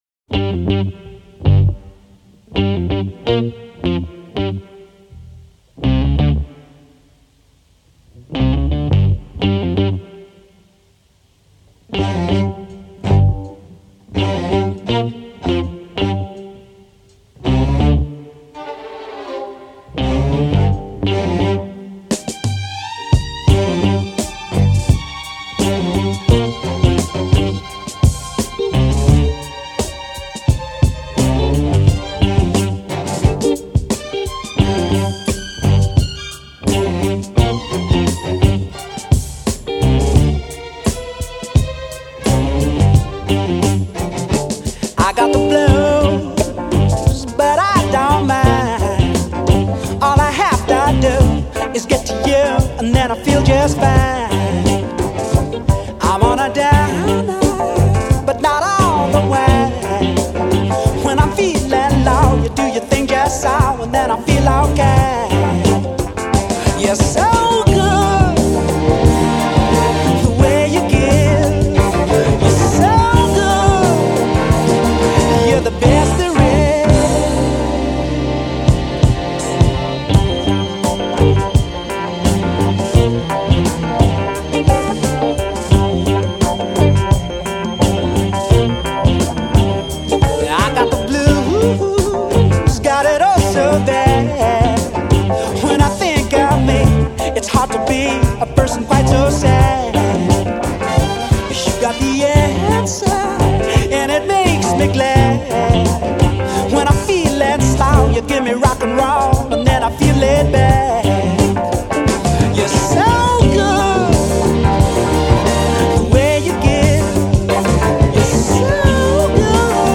Rainy bassline blues - as famously sampled by Eminem.